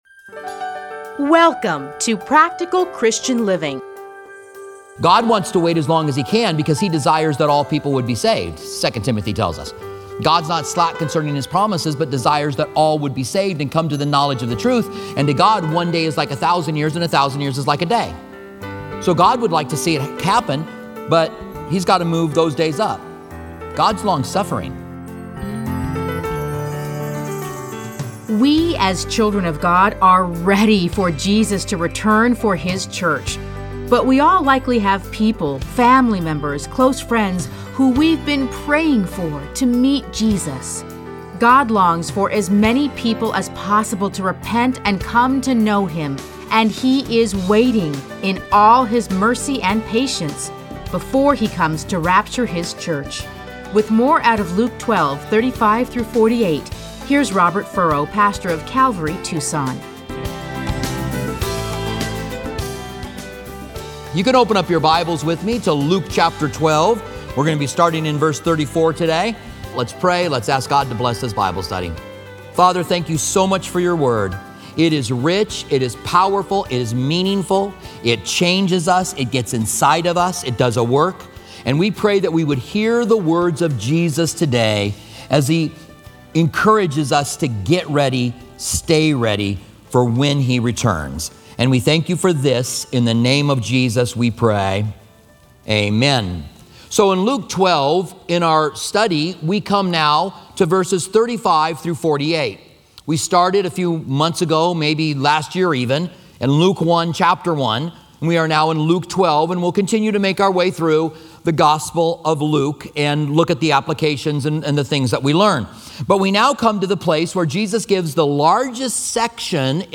Listen to a teaching from Luke 12:35-48.